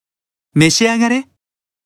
Chat Voice Files